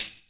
Perc (10).wav